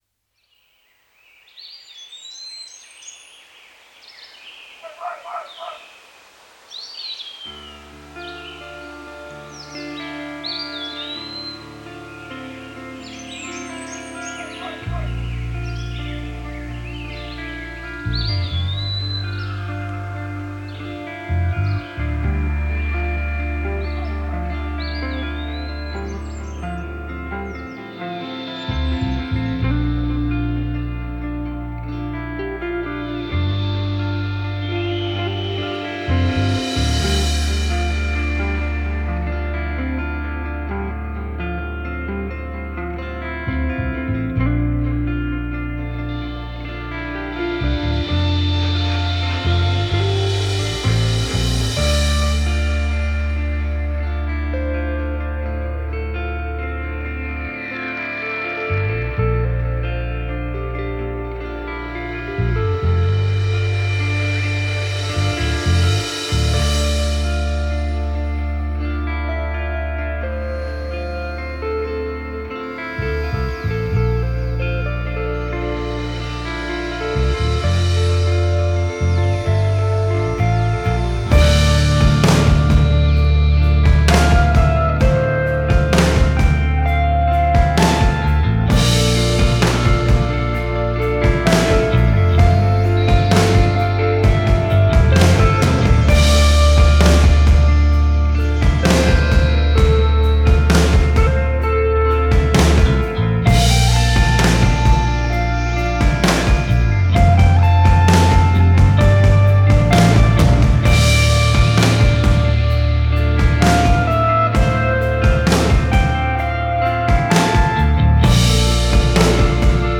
Жанр: Instrumental.